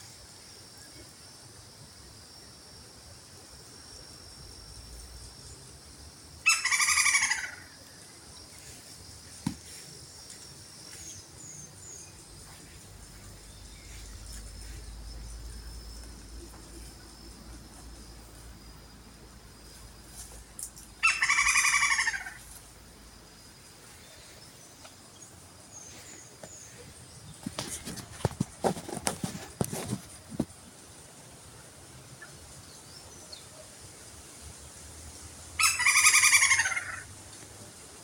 Lineated Woodpecker (Dryocopus lineatus)
Location or protected area: Reserva Privada San Sebastián de la Selva
Condition: Wild
Certainty: Recorded vocal
carpintero-garganta-estriada.mp3